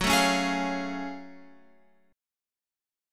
F#mM9 chord {2 0 3 1 x 2} chord